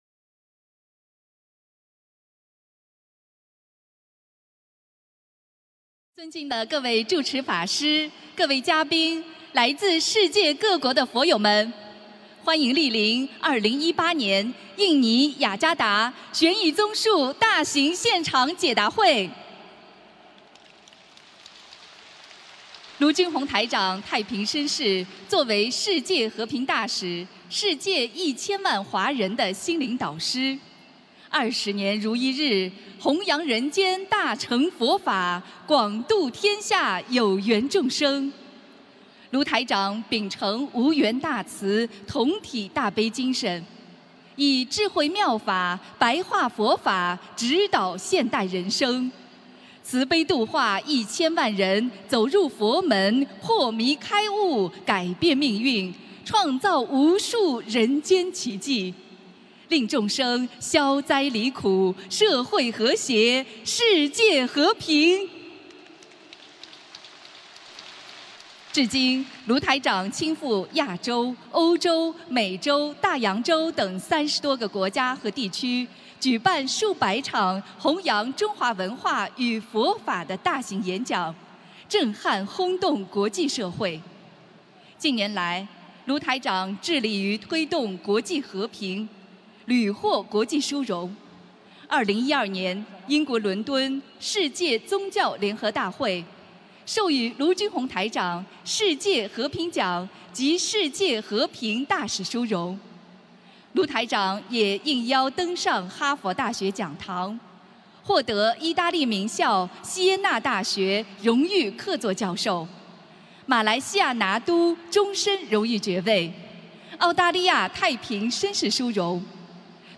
2018年3月11日印尼解答会开示（视音文图） - 2018年 - 心如菩提 - Powered by Discuz!